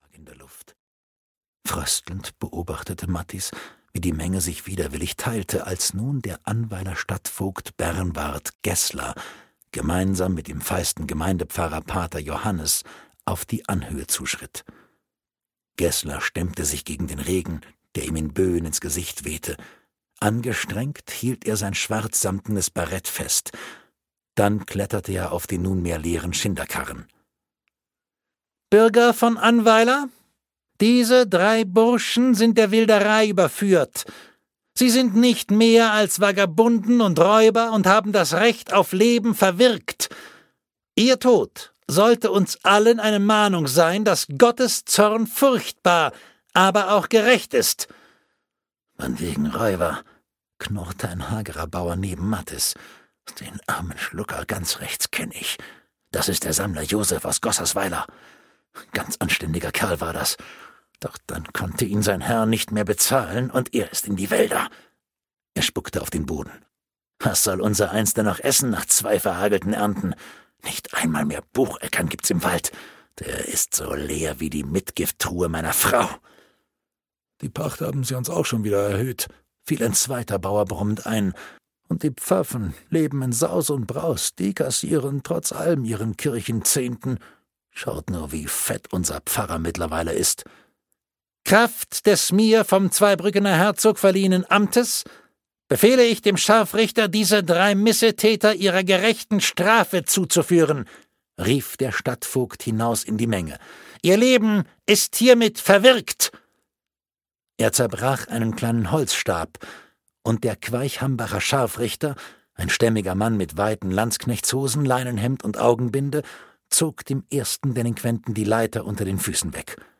Die Burg der Könige - Oliver Pötzsch - Hörbuch